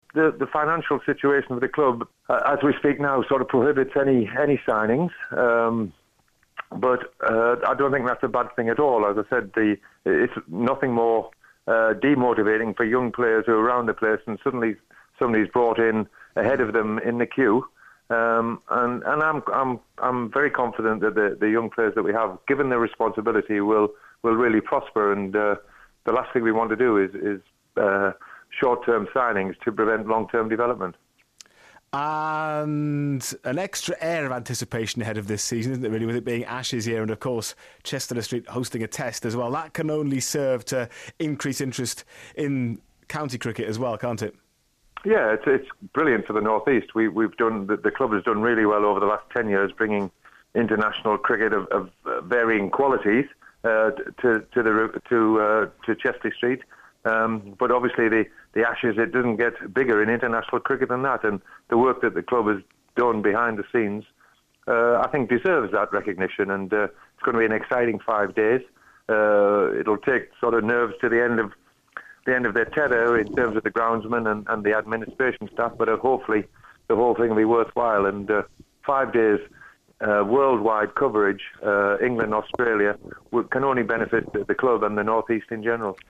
INTERVIEW ON BBC NEWCASTLE'S TOTAL SPORT SHOW. HE TALKS ABOUT YOUNG PLAYERS COMING THROUGH THE RANKS, OVERSEAS SIGNINGS AND THE ASHES.